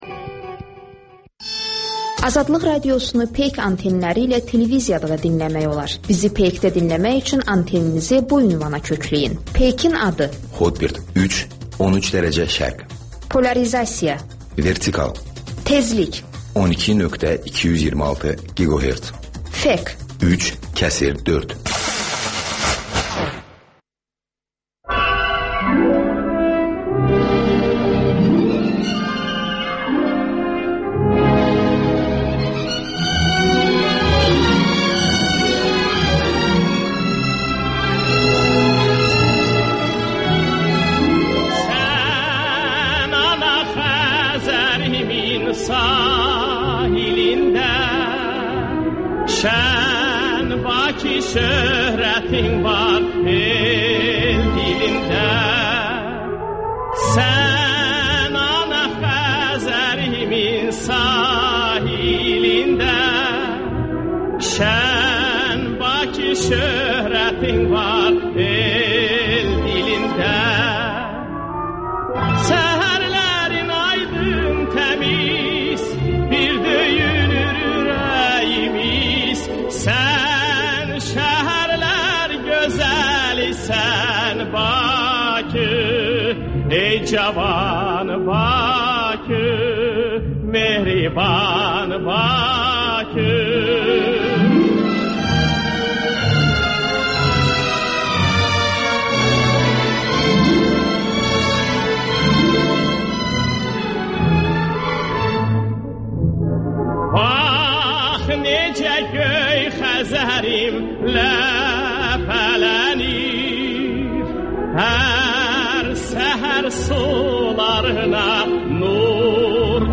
Müxbirlərimizin həftə ərzində hazırladıqları ən yaxşı reportajlardan ibarət paket (Təkrar)